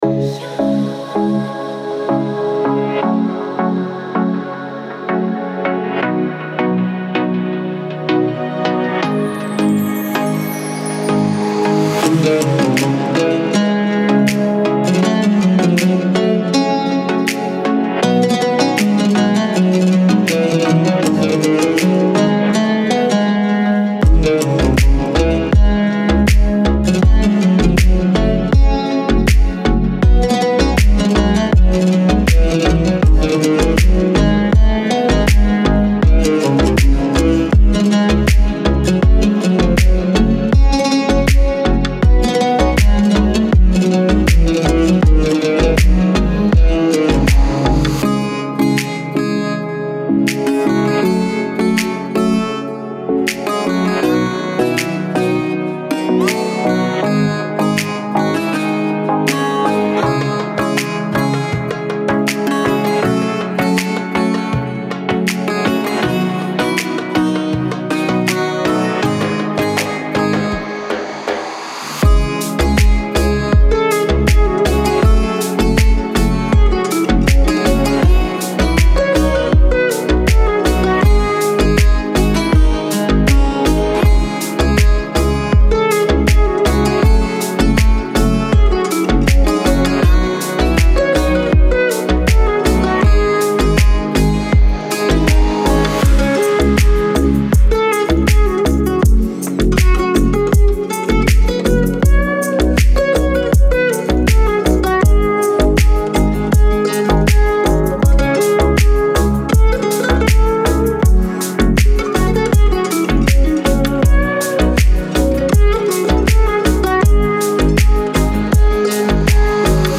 ریتمیک آرام